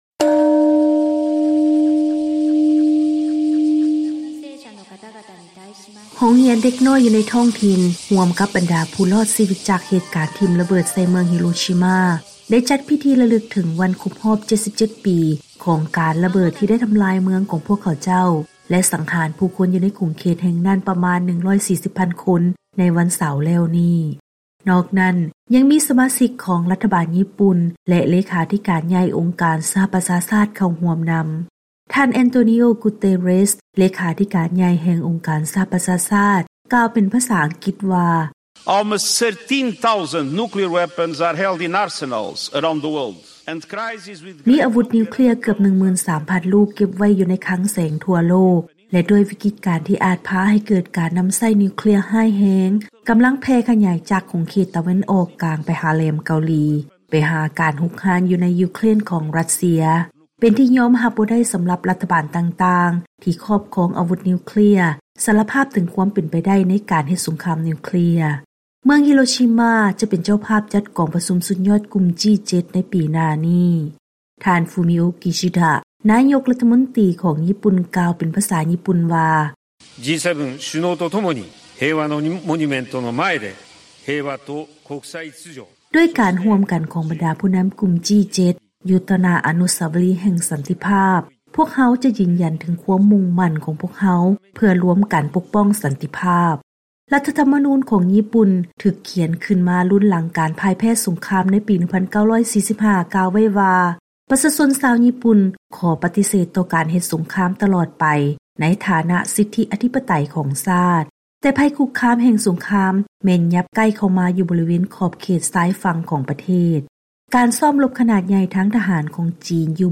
ລາຍງານກ່ຽວກັບ ການຈັດພິທີລະນຶກຄົບຮອບການຖືກລະເບີດຂອງຍີ່ປຸ່ນ, ໃນຂະນະທີ່ໄພຄຸກຄາມຈາກ ຈີນ ໄດ້ທໍາລາຍຂໍ້ຫ້າມທາງທະຫານ.